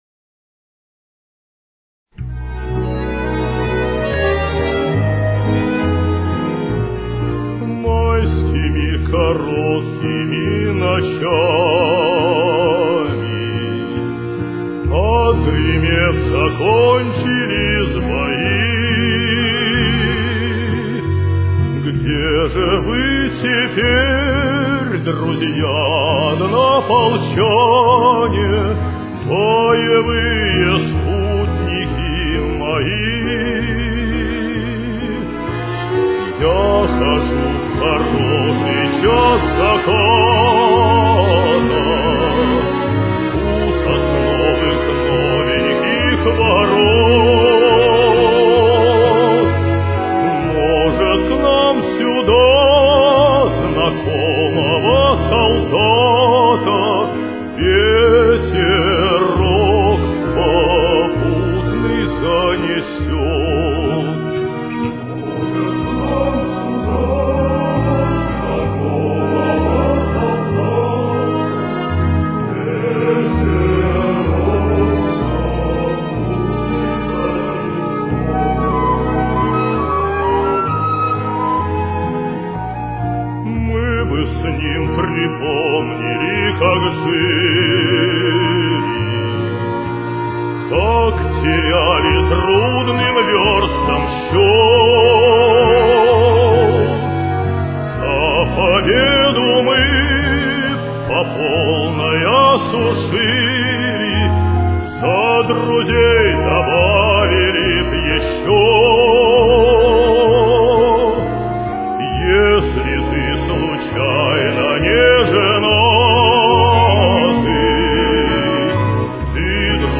Ми-бемоль минор. Темп: 68.